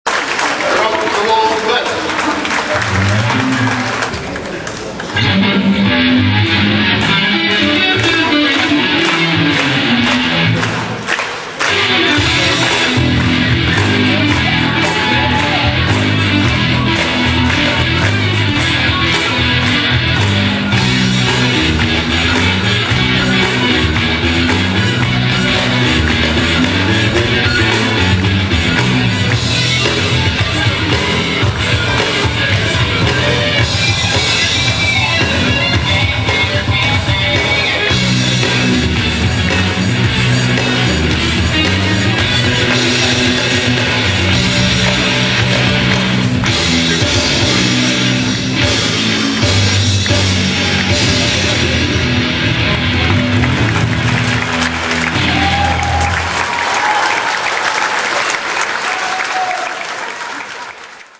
Это запись с концерта в Ялте.